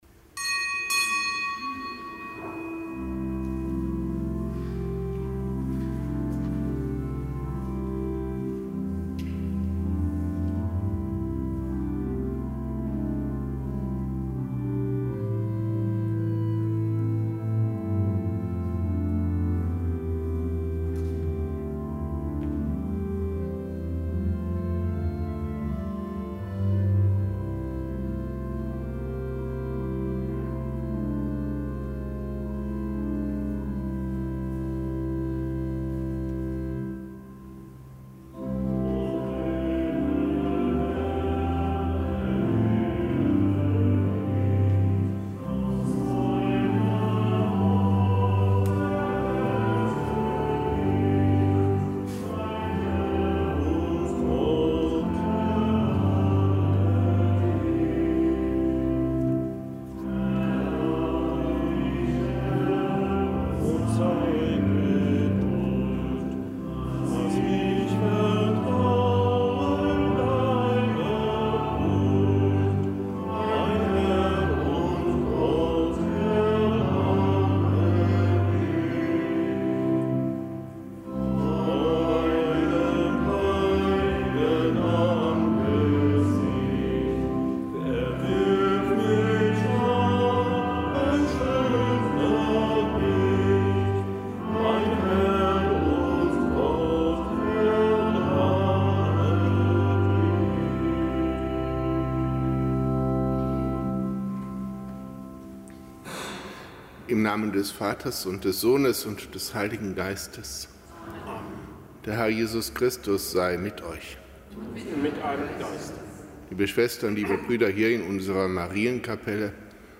Kapitelsmesse am Freitag der vierten Fastenwoche
Kapitelsmesse aus dem Kölner Dom am Freitag der vierten Fastenwoche.